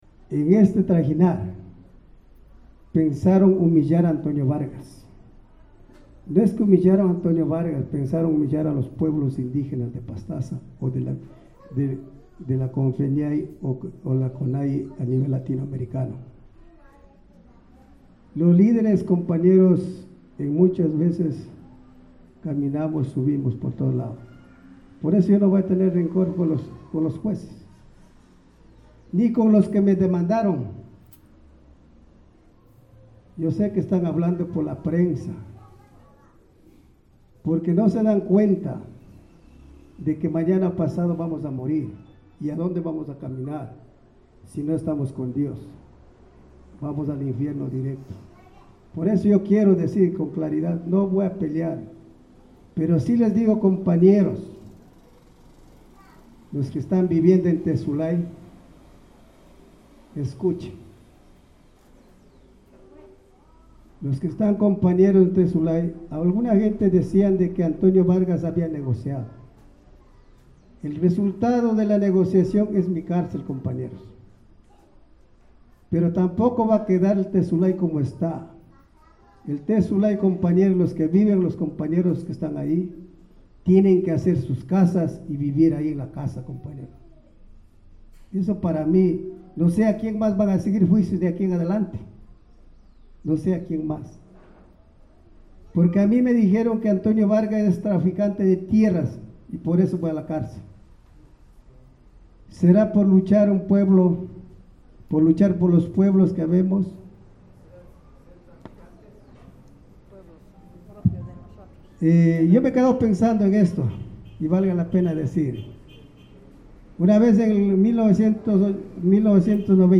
En este mismo lugar se había preparado el escenario para los discursos de felicitación, Antonio Vargas, en su extensa intervención, tras invocar a Dios en su agradecimiento, dijo no tener rencor hacia nadie, y que han intentado acallarle, pero no lo han conseguido.